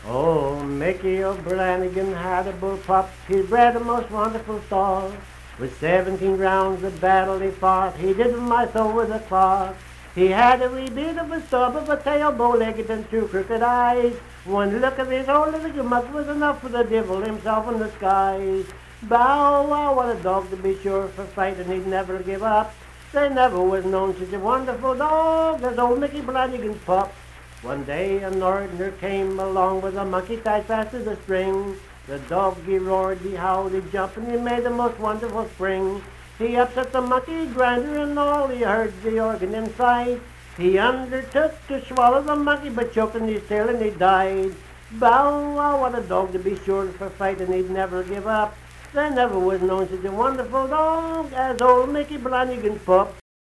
Unaccompanied vocal music
Performed in Hundred, Wetzel County, WV.
Hymns and Spiritual Music
Voice (sung)